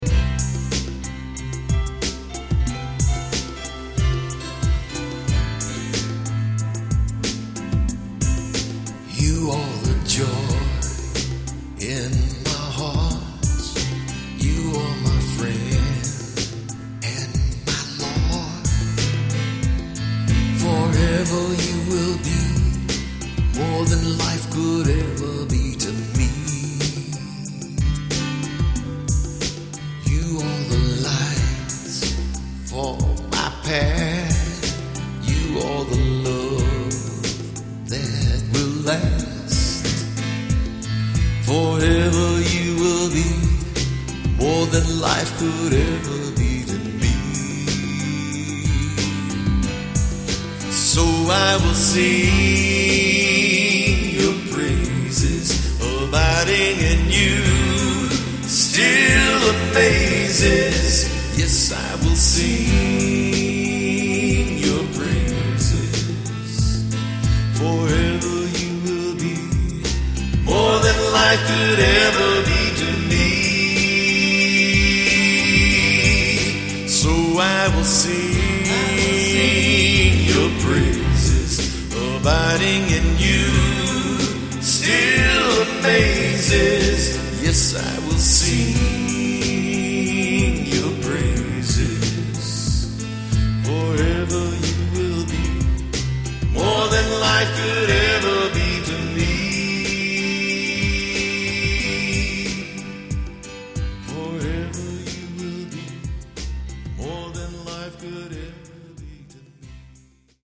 ARTIST ACCOMPANIMENT TRACKS